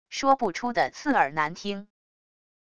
说不出的刺耳难听wav音频